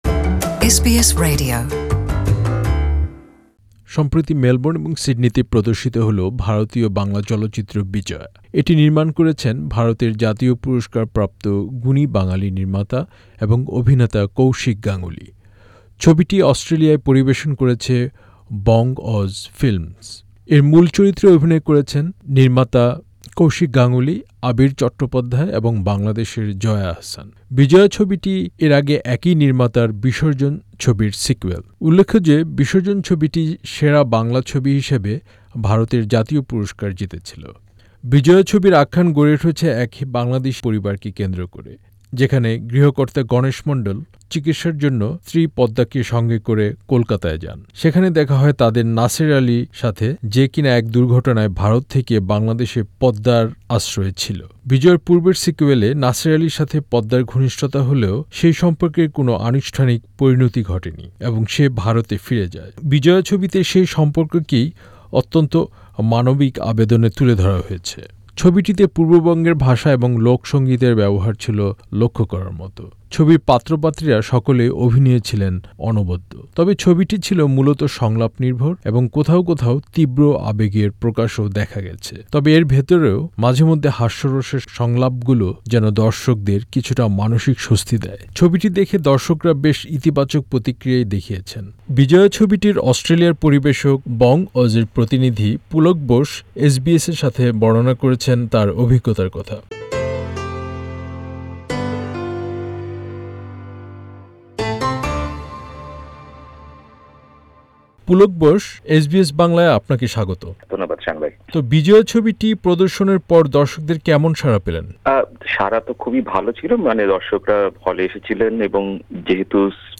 সাক্ষাৎকারটি বাংলায় শুনতে উপরের অডিও প্লেয়ারটিতে ক্লিক করুন।